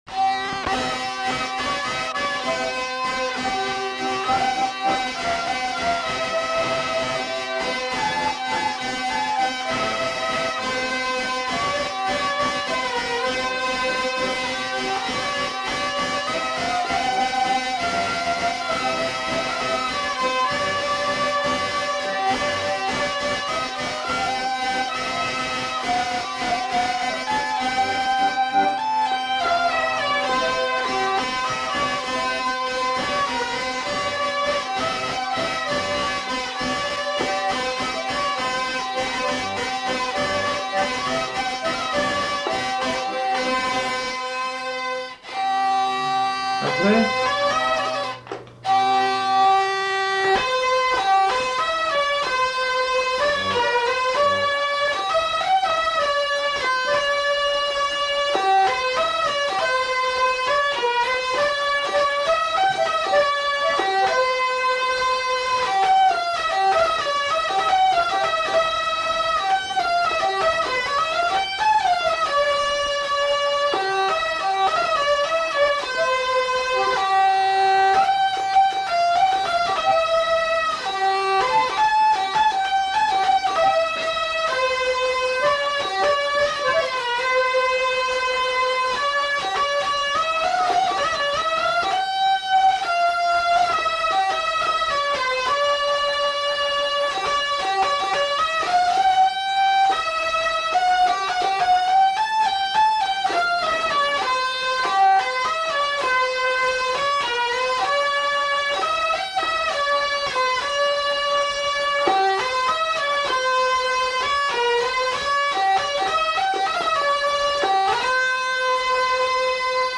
Aire culturelle : Gabardan
Lieu : Herré
Genre : morceau instrumental
Instrument de musique : vielle à roue ; accordéon diatonique
Danse : valse